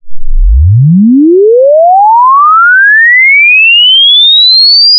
Single frequency sweep, 0 to 5 kHz, 5 seconds.
sweep.48k.wav